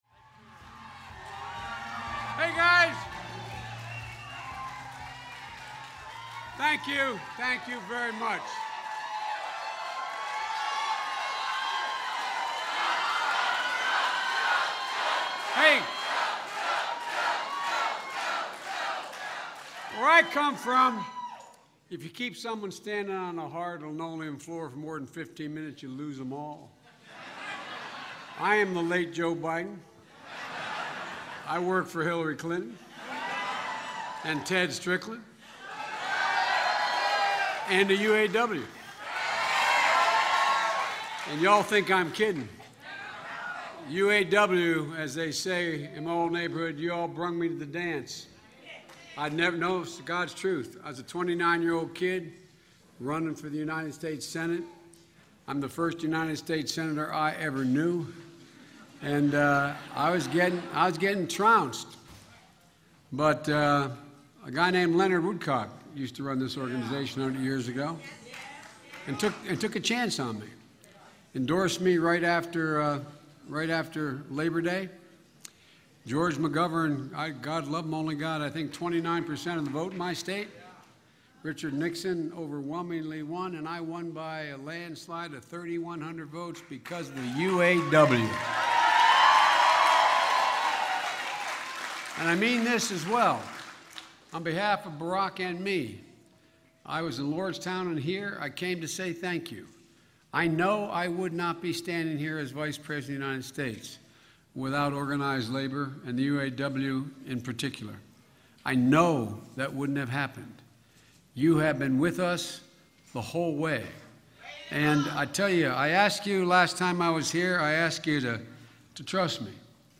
U.S. Vice President Joe Biden speaks at a campaign event for Democratic presidential nominee Hillary Clinton in Parma, OH